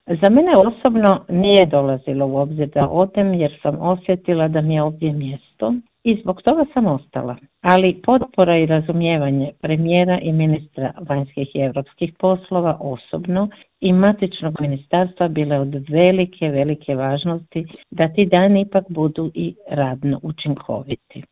telefonskom Intervjuu Media servisa
hrvatsku veleposlanicu iz Kijeva Anicu Djamić